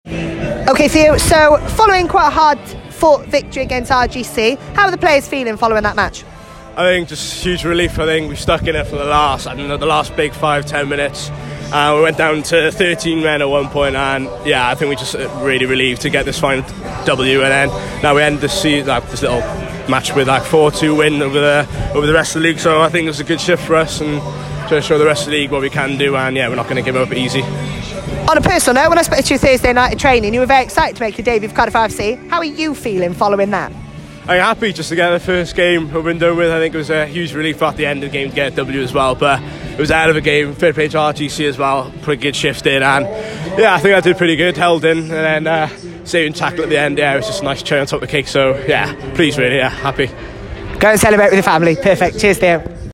Post Match Interviews